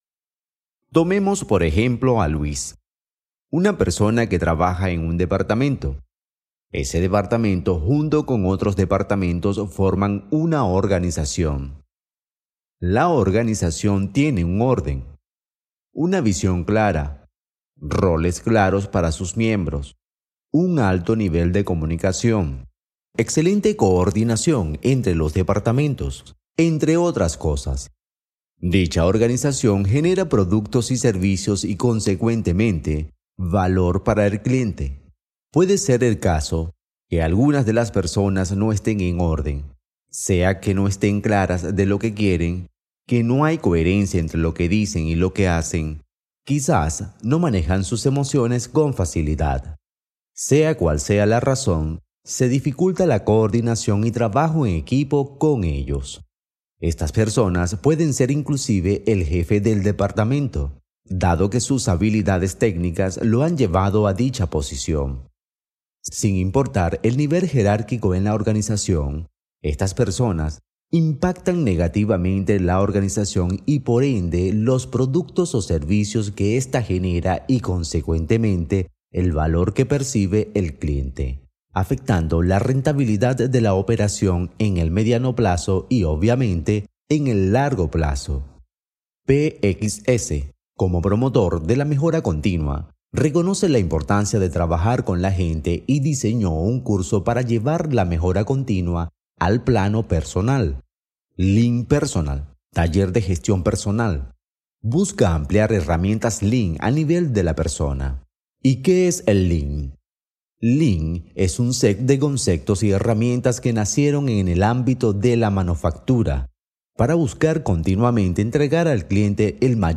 Voz off para video corporativo, empresa DPX